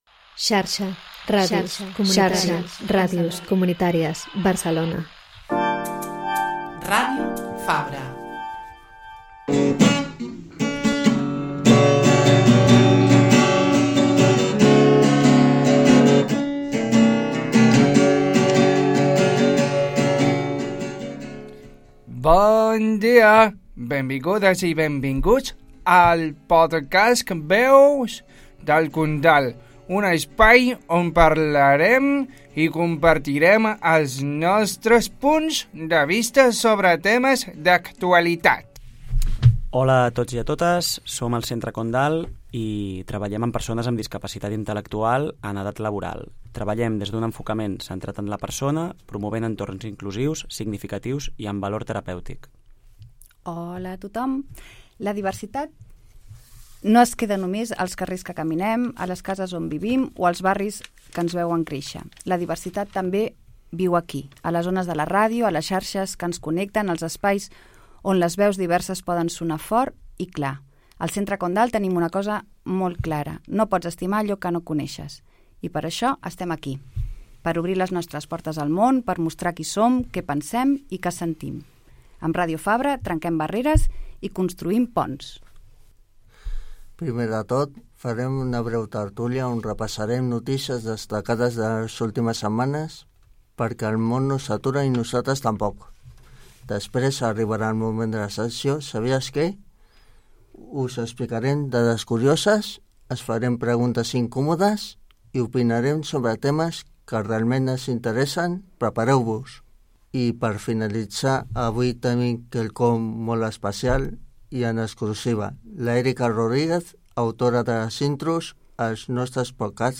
Dades curioses, tertúlia i música en directe.